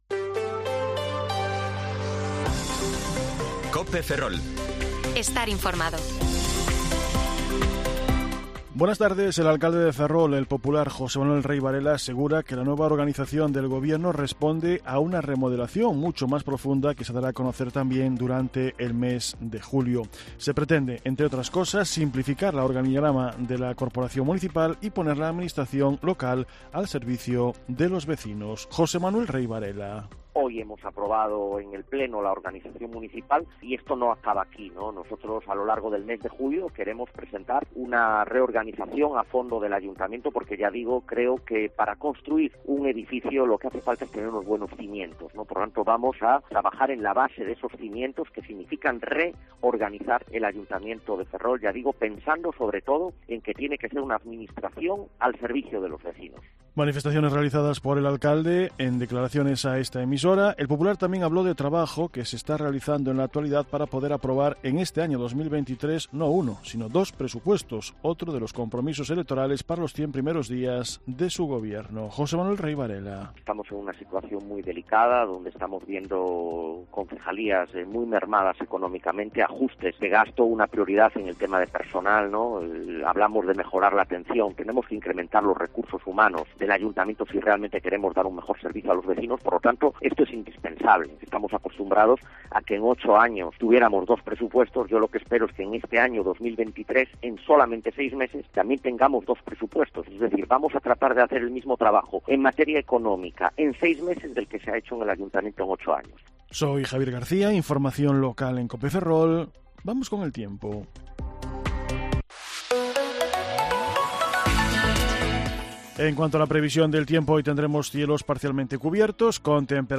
Informativo Mediodía COPE Ferrol 29/6/2023 (De 14,20 a 14,30 horas)